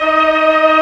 Index of /90_sSampleCDs/Giga Samples Collection/Organ/Barton Melo 16+8